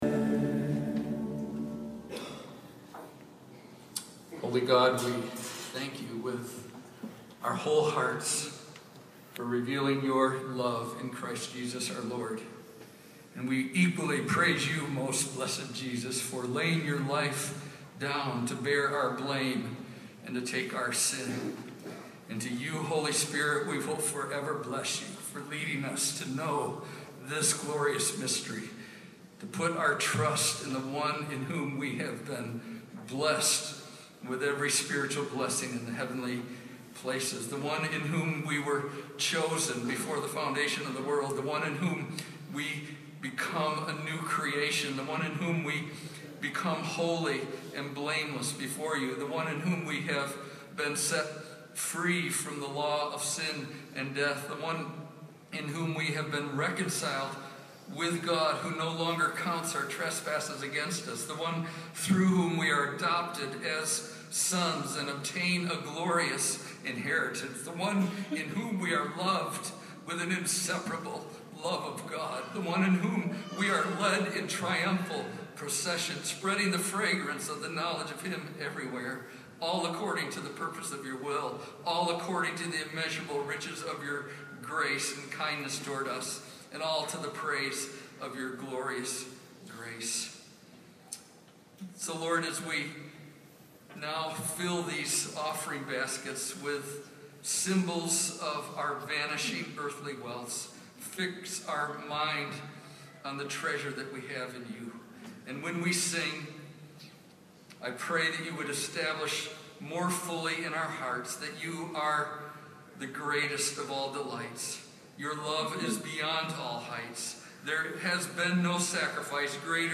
I got my phone ready and recorded his prayer.